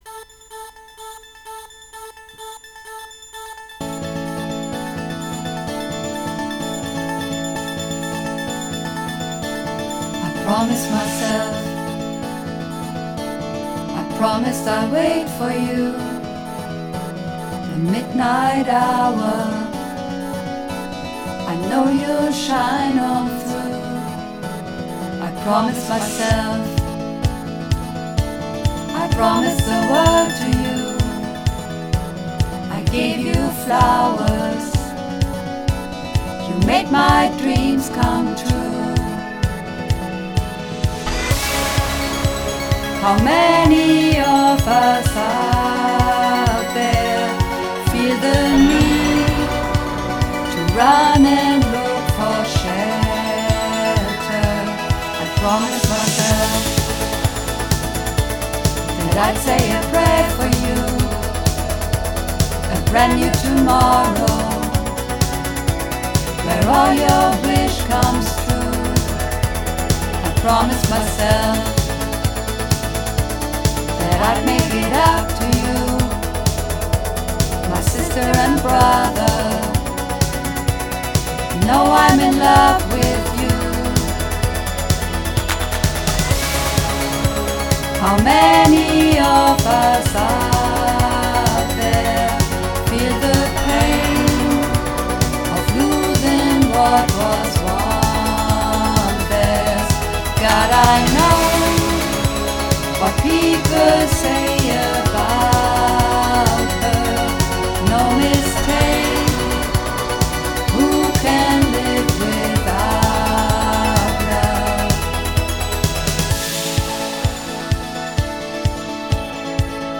Übungsaufnahmen - I Promised Myself
I Promised Myself (Mehrstimmig)